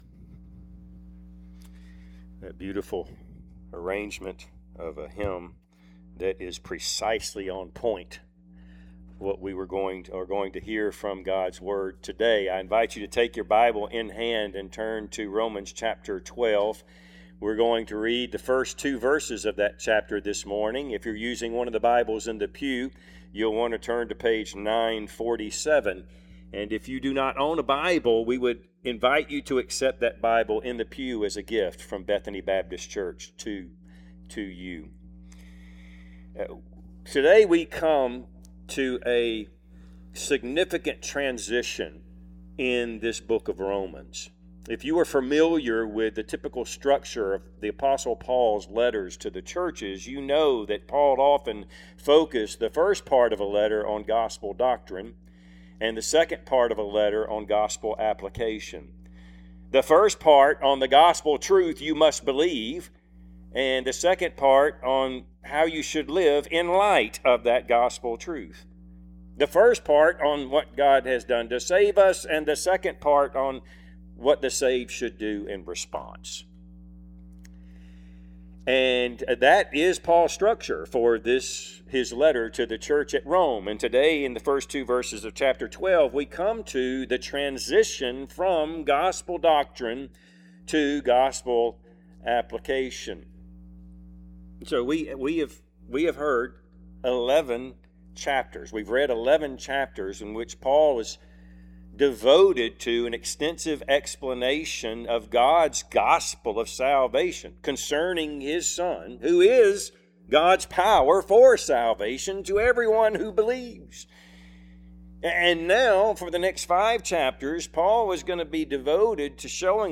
Passage: Romans 12:1-2 Service Type: Sunday AM